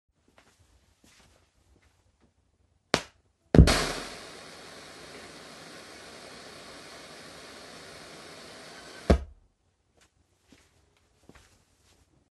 Fractal Axe FM3 weißes Rauschen über Lautsprecher beim Hochfahren
Hallo zusammen, ich habe bei meinem FM3 ein weißes rauschen beim Hochfahren, wenn es hochgefahren ist ist es wieder ruhig.